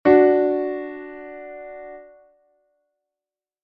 Consonancia Imperfecta 3ª M (Re - Fa#)